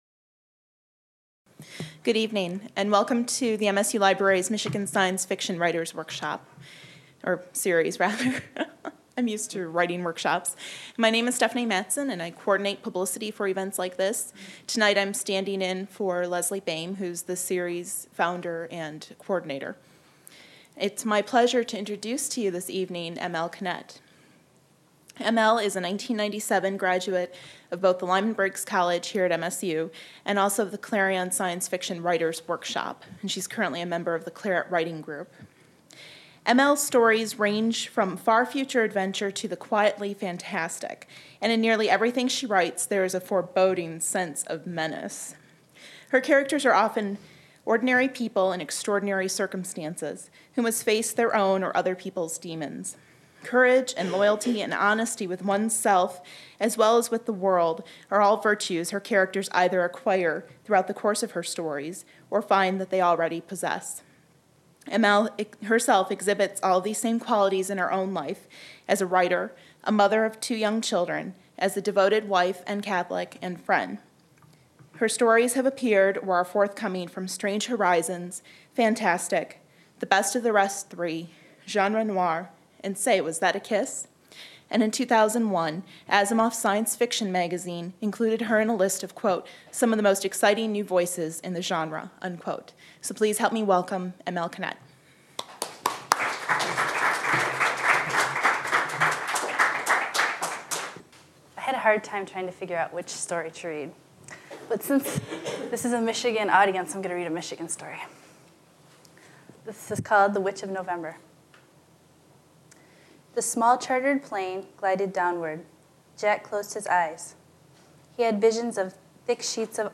Held in the Main Library.